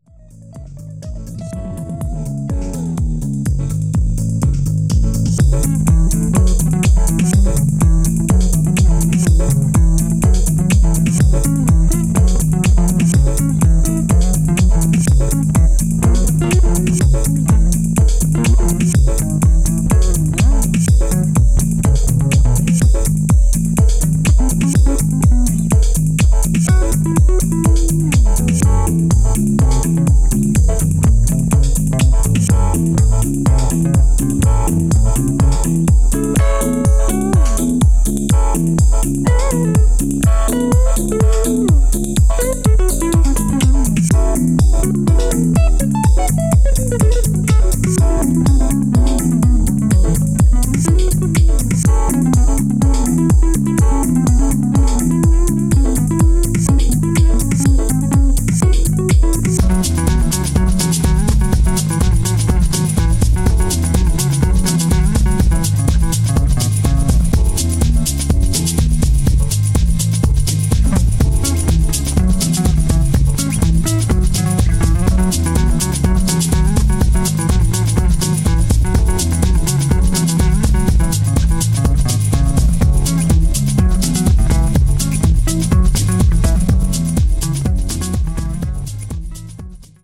ジャンル(スタイル) DEEP HOUSE / HOUSE